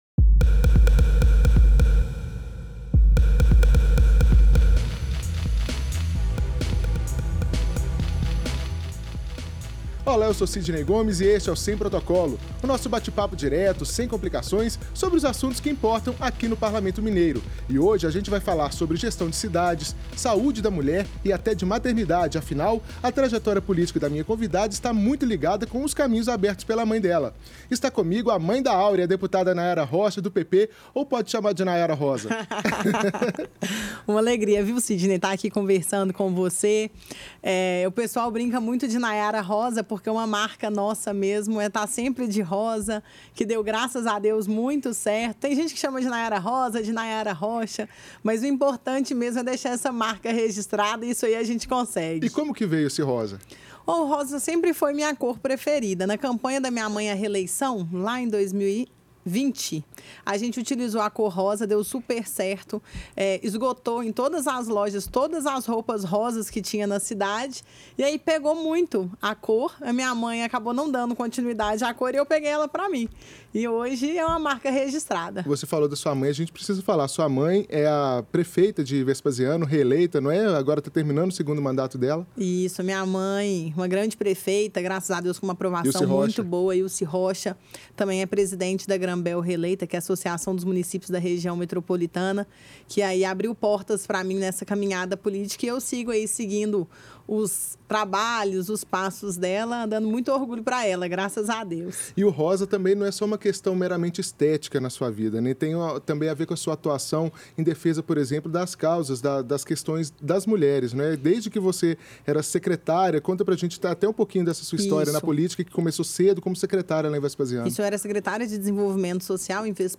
Nesta conversa